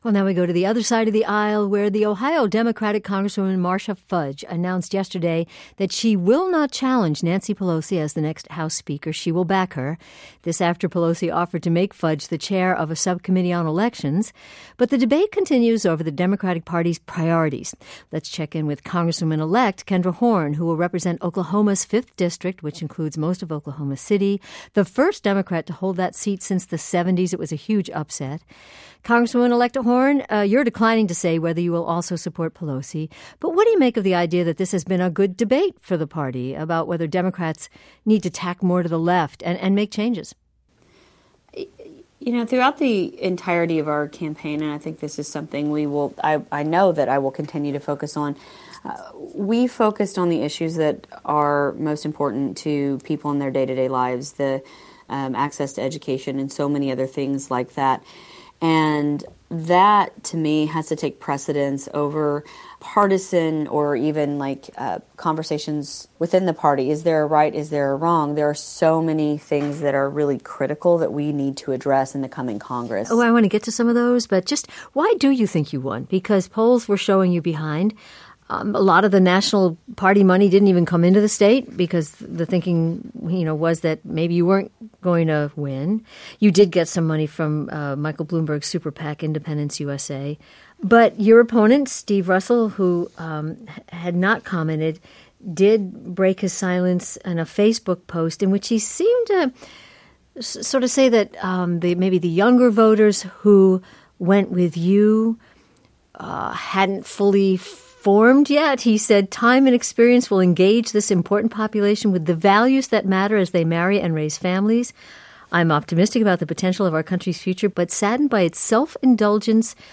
Here & Now's Robin Young talks with U.S. Rep.-elect Kendra Horn, who will represent the Oklahoma district that includes most of the capital, Oklahoma City.